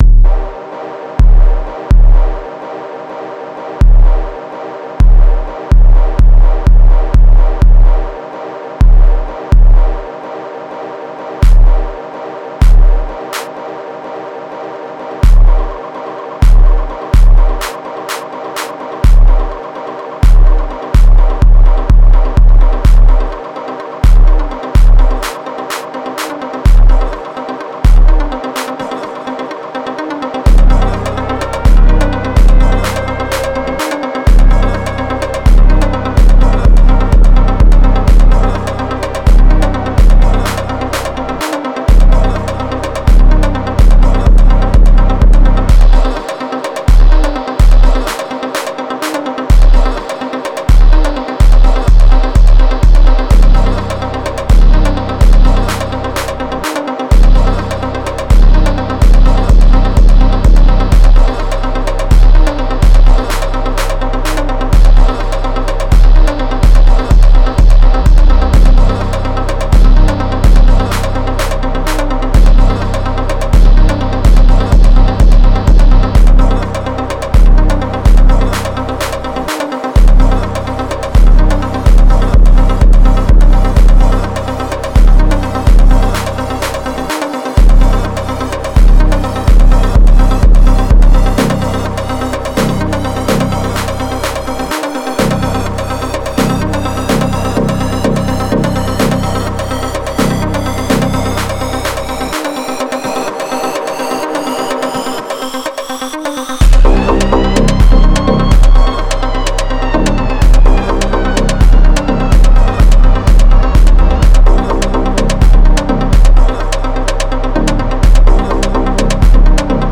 Genre : Gqom